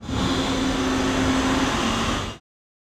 ambience
Dock Ambience - Crane, Loading, Tool, Movement, Creak